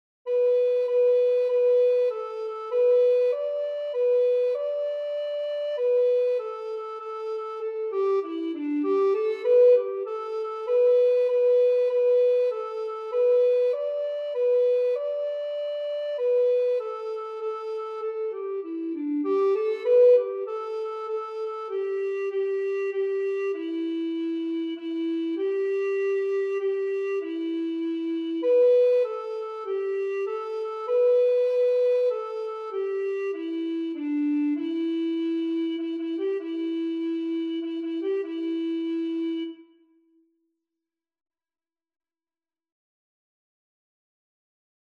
dit liedje is pentatonisch